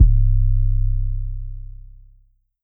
808 (ESP).wav